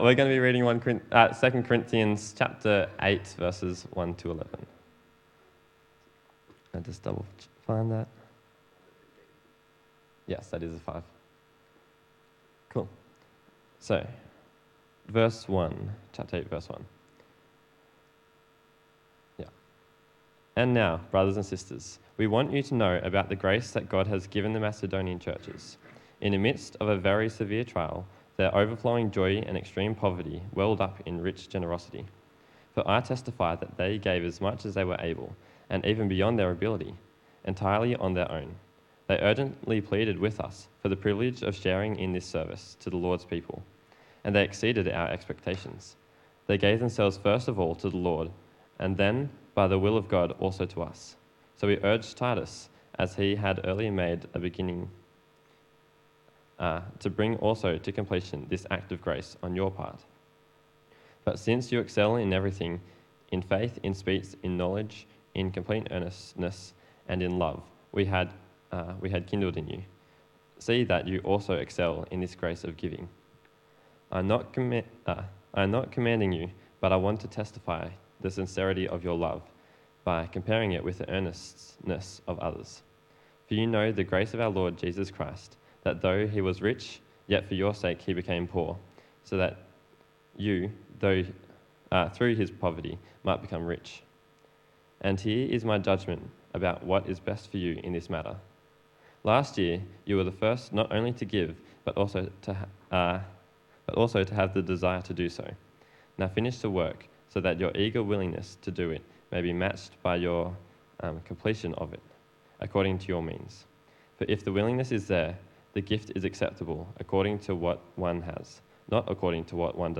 Talk Type: Bible Talk